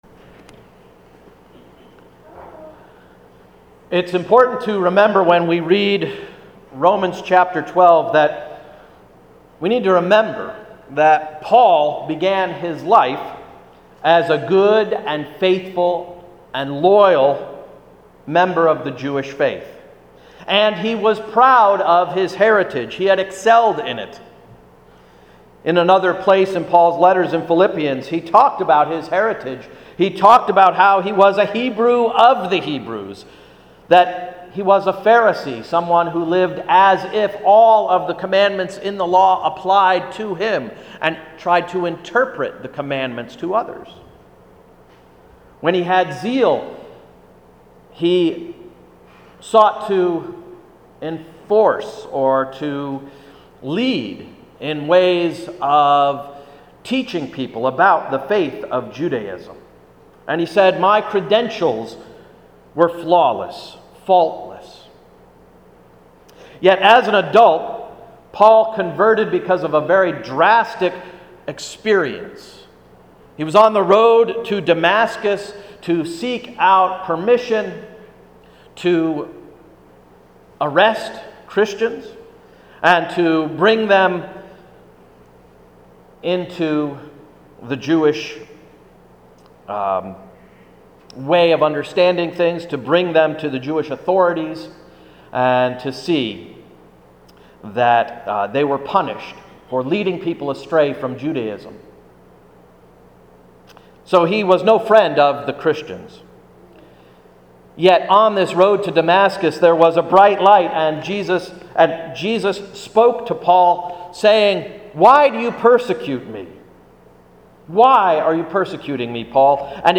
Sermon of August 31–“Embracing Enemies”